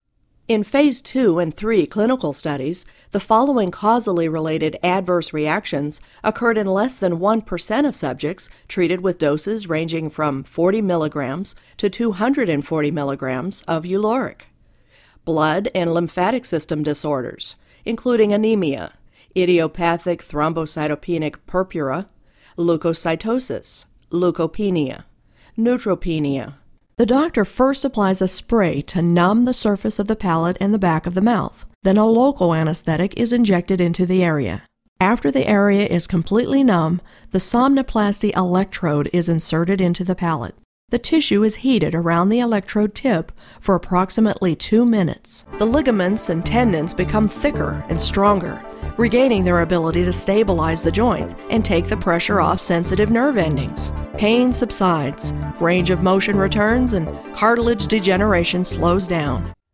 Conversational, Real Person, Sincere, Genuine, Expressive
middle west
Sprechprobe: eLearning (Muttersprache):